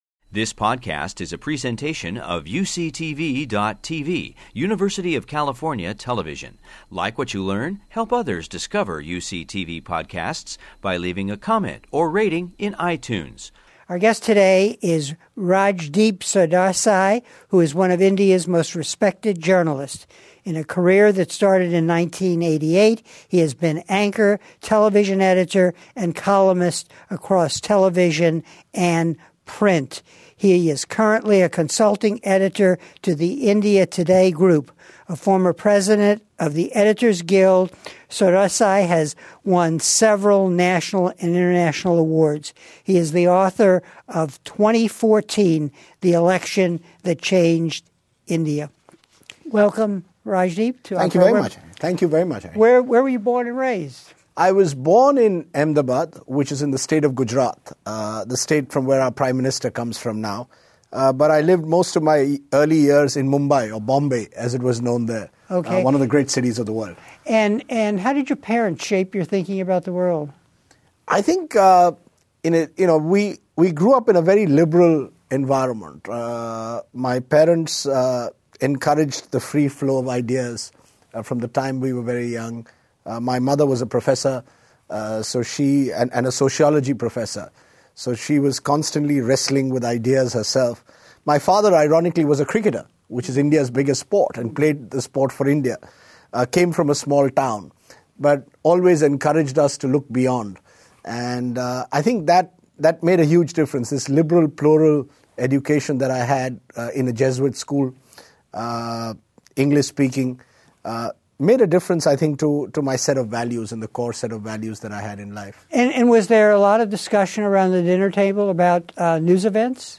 In these lively and unedited interviews, distinguished men and women from all over the world talk about their lives and their work. Interviews span the globe and include discussion of political, economic, military, legal, cultural, and social issues shaping our world.